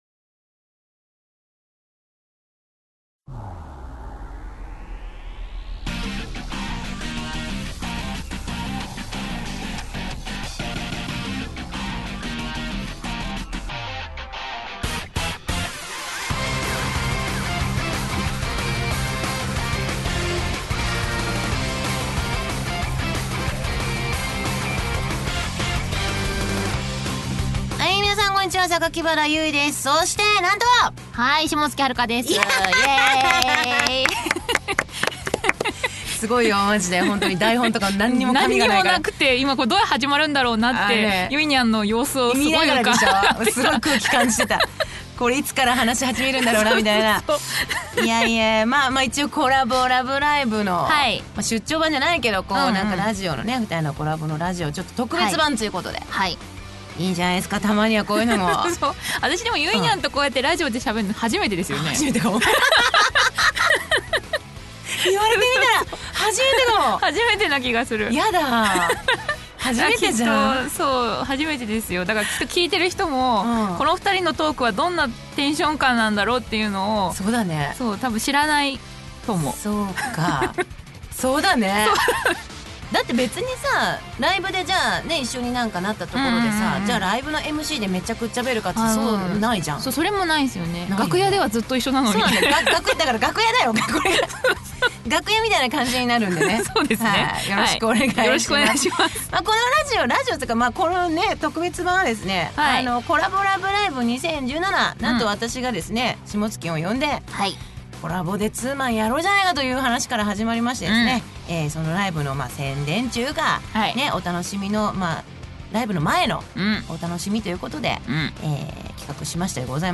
『Collabo★LOVE×Live2017』のために収録した特別版ラジオ！！ ライブに行こうか迷っている方には是非聴いてほしい！
『Collabo×Radio Vol.1』 パーソナリティ：榊原ゆい＆霜月はるか Information ご来場ありがとうございました！